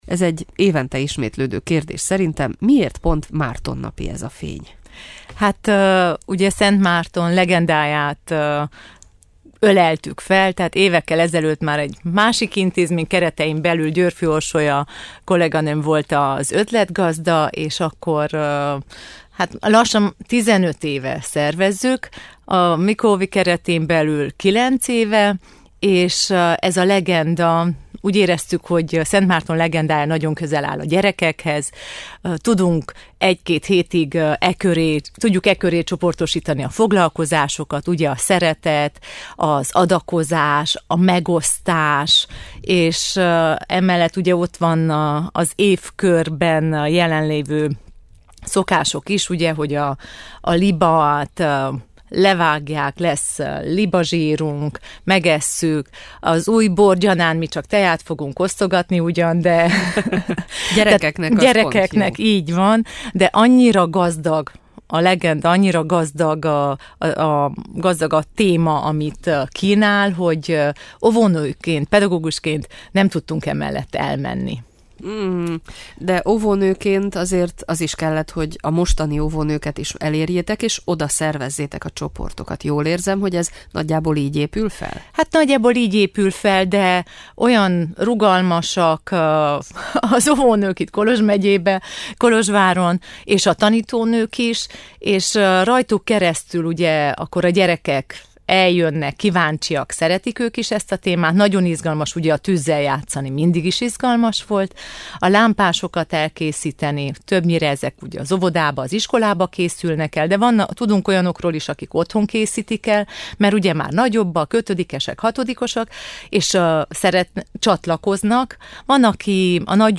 Házigazda